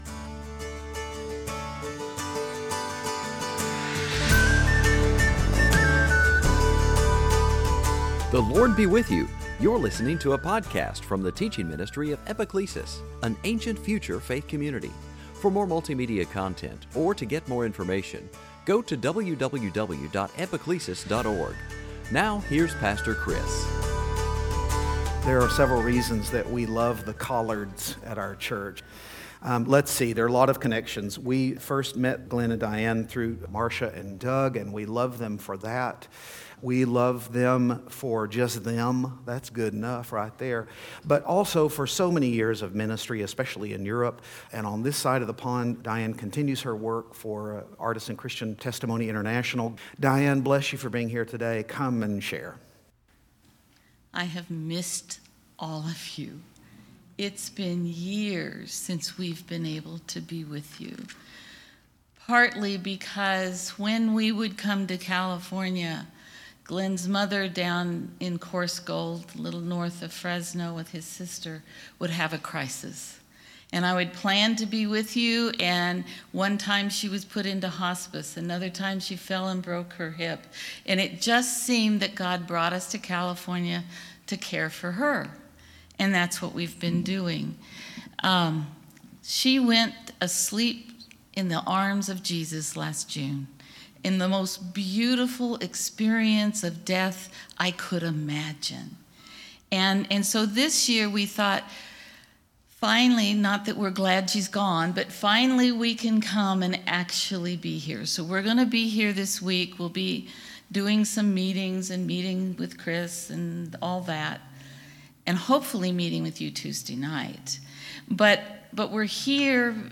Series: Sunday Teaching
Service Type: Season after Pentecost